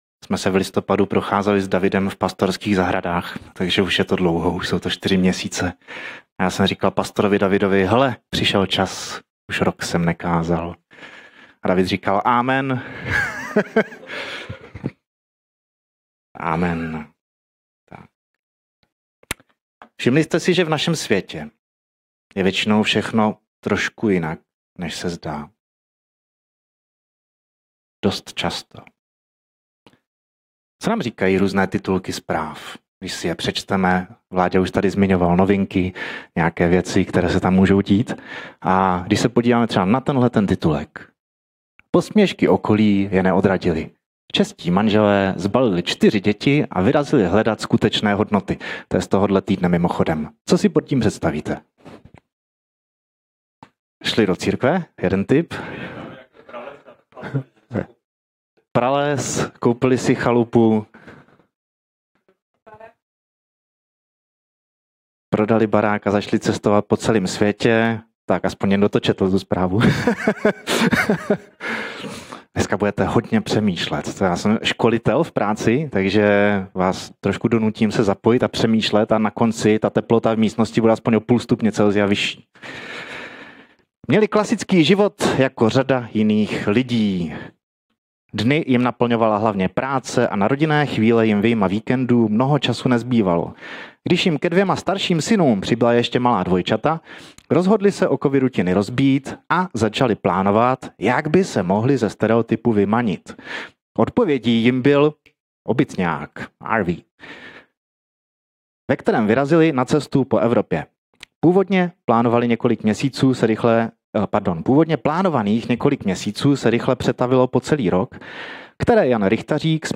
Pro váš užitek zveřejňujeme výběr z nahrávek biblických kázání Apoštolské církve ve Vyškově.